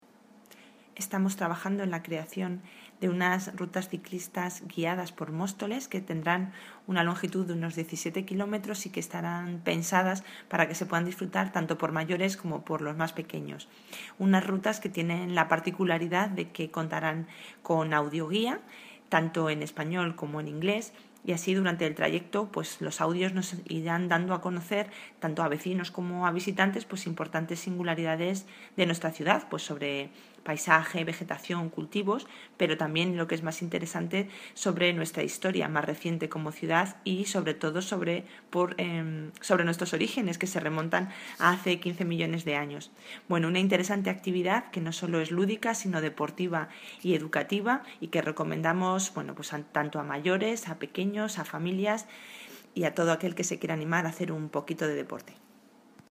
Audio - Paloma Tejero (Concejal de Movilidad) Sobre Rutas Ciclo turistas